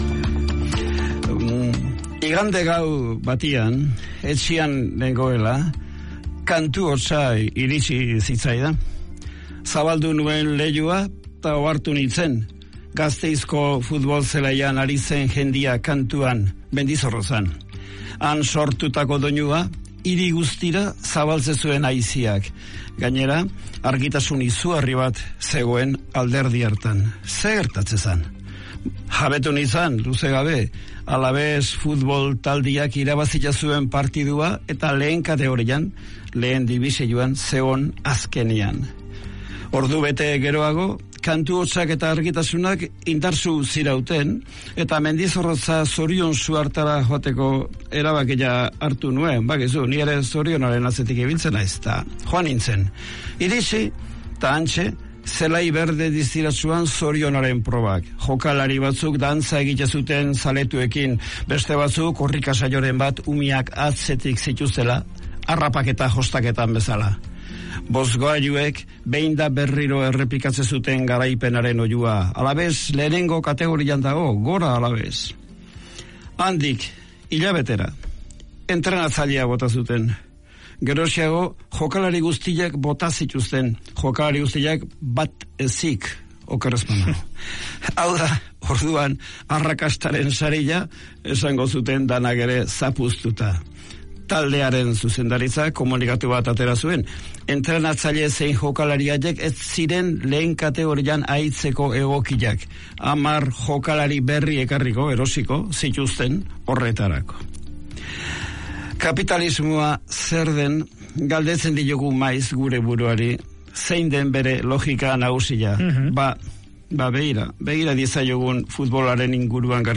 Bernardo Atxaga. Euskadi Irratia, 2016-10-17